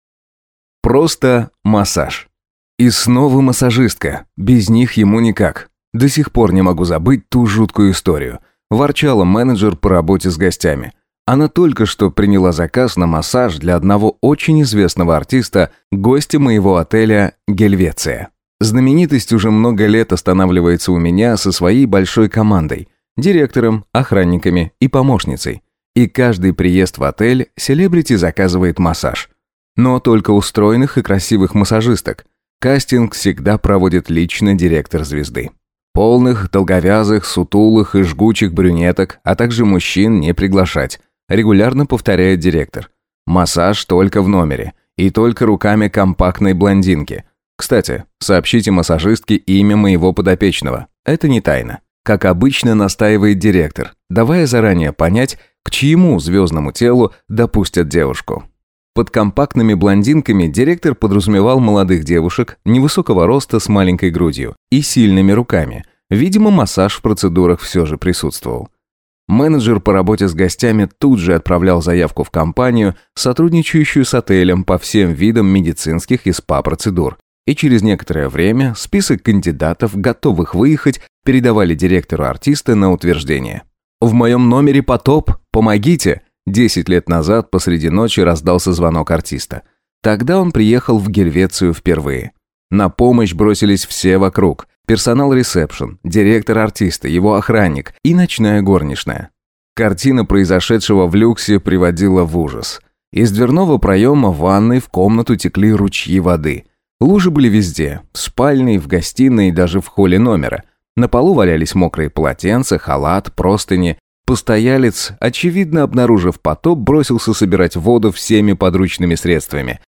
Аудиокнига «Upgrade». Записки отельера | Библиотека аудиокниг